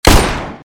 手枪格洛克射击1.mp3